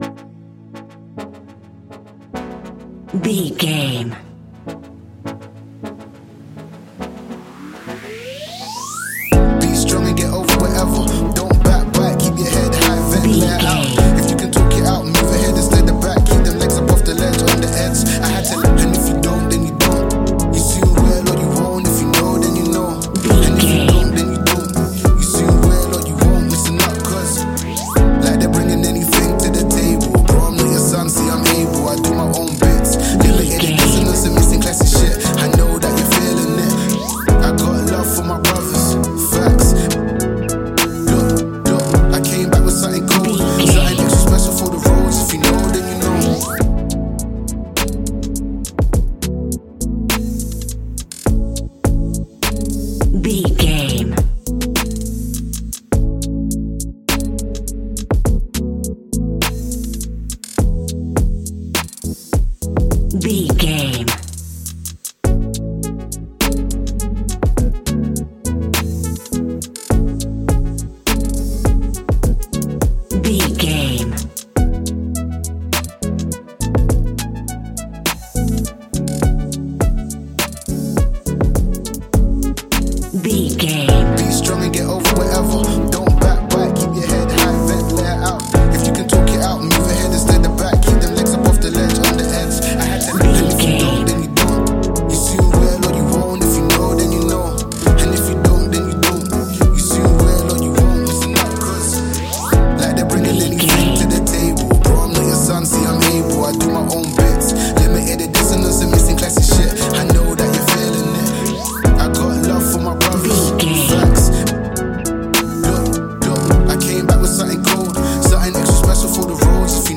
Ionian/Major
laid back
Lounge
sparse
new age
chilled electronica
ambient
atmospheric
morphing
instrumentals